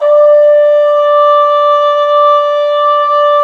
Index of /90_sSampleCDs/Roland LCDP12 Solo Brass/BRS_Cup Mute Tpt/BRS_Cup Ambient